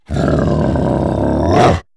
12. Ambush Growl.wav